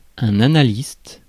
Ääntäminen
Ääntäminen France: IPA: [a.na.list] Haettu sana löytyi näillä lähdekielillä: ranska Käännös Konteksti Ääninäyte Substantiivit 1. analyst tietojenkäsittely, psykiatria US 2. analytical chemist Suku: m .